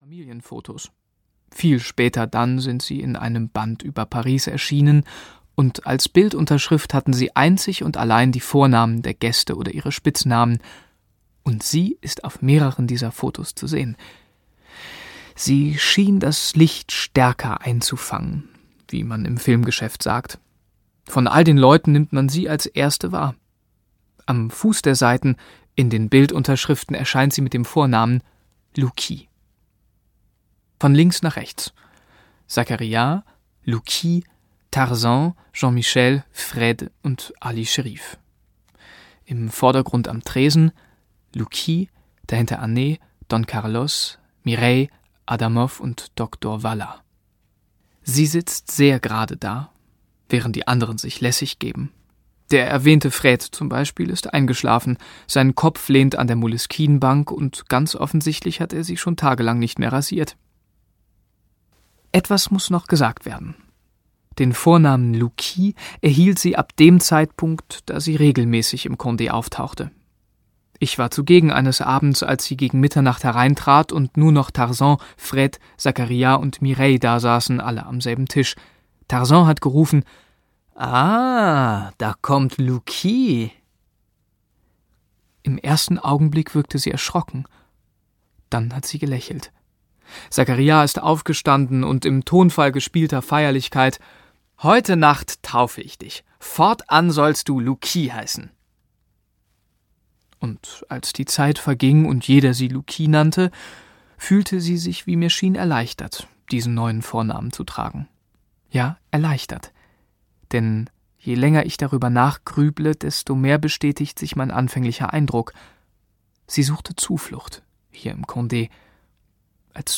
Im Café der verlorenen Jugend - Patrick Modiano - Hörbuch